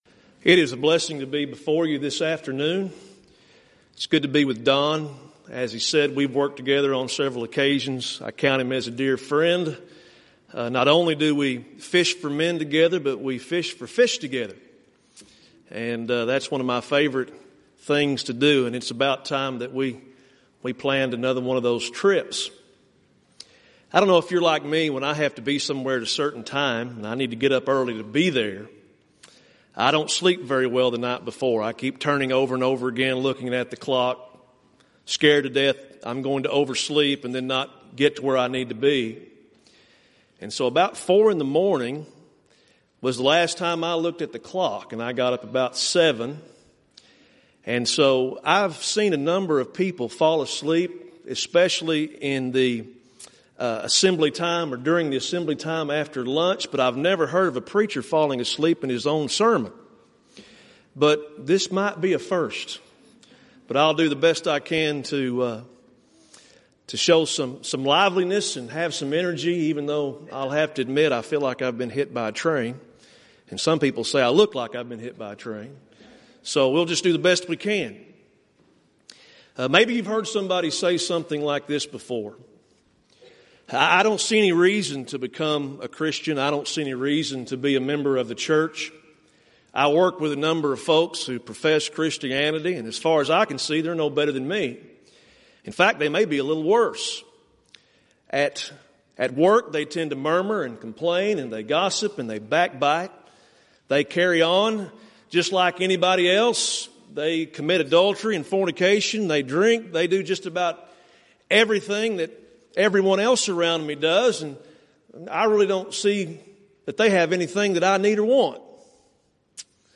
Event: 29th Annual Southwest Lectures Theme/Title: Proclaiming Christ: Called Unto Salvation